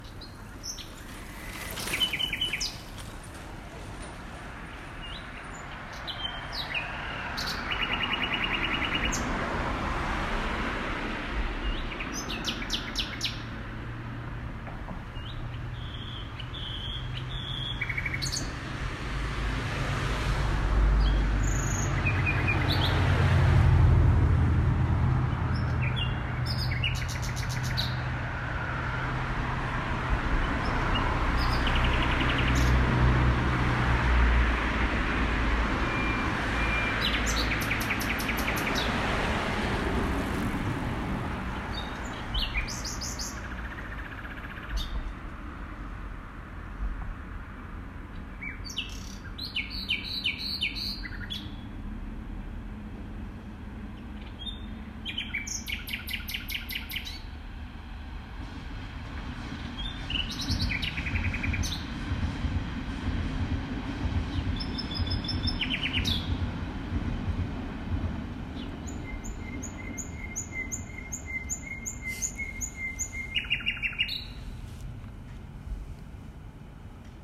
nachtigall-berlin.mp3